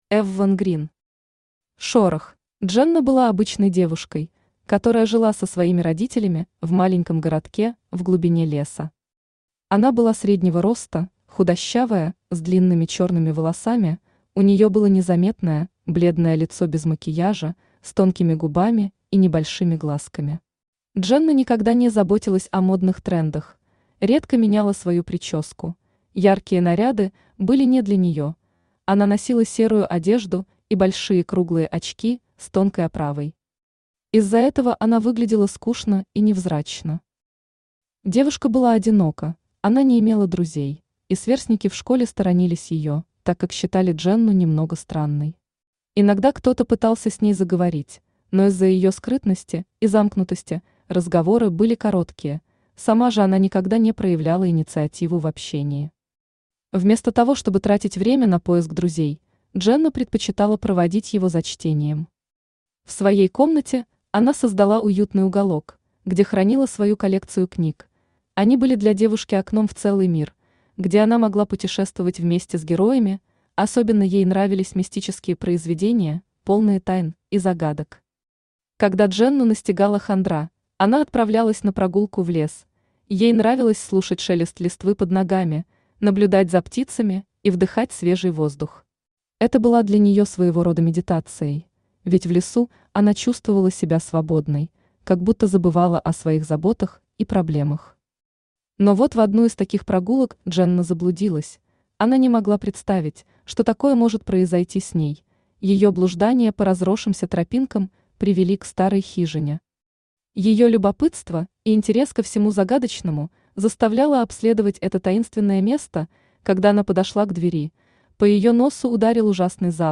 Aудиокнига Шорох Автор Evvvan Green Читает аудиокнигу Авточтец ЛитРес.